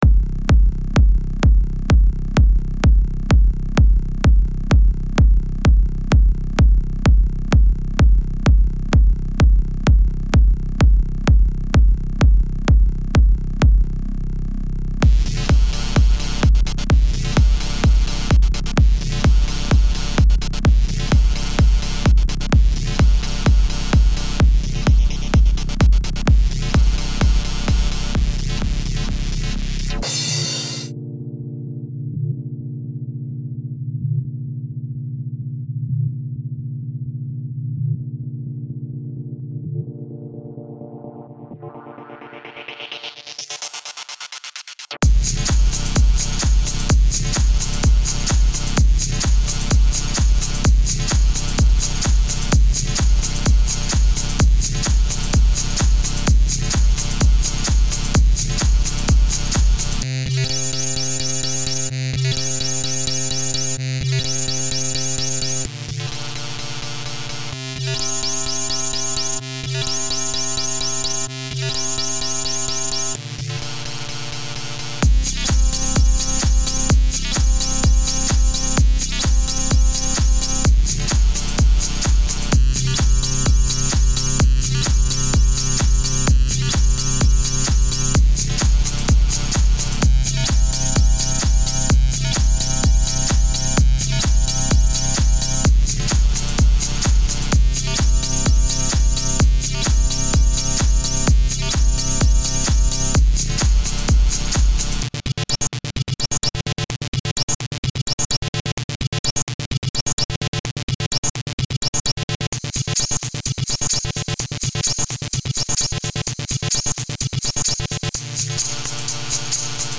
Genre: Techno